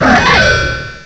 cry_aegislash.aif